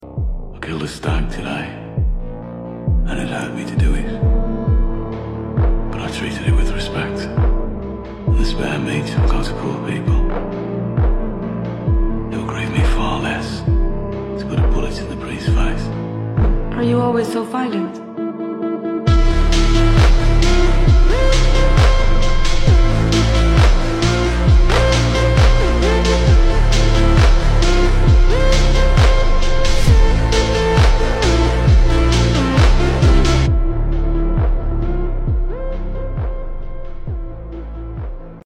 ultra slowed & reverb